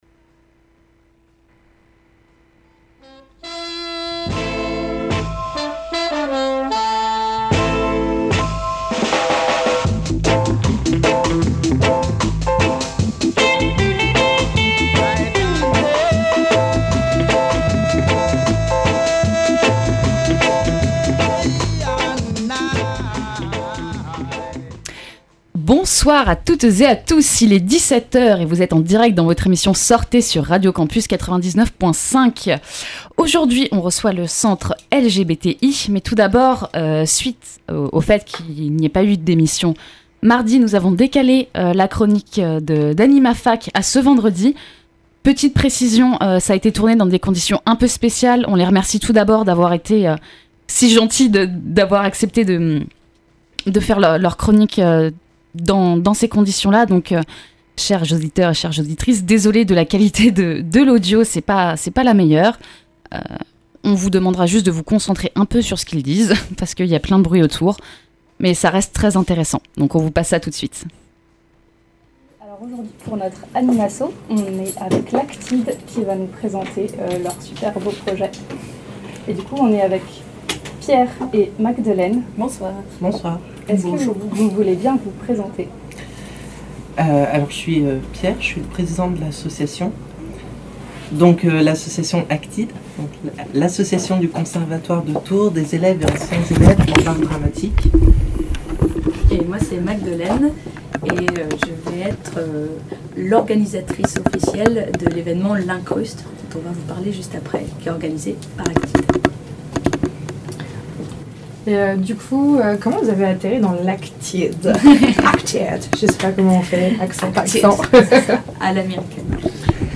tant bien que mal, désolée pour la qualité…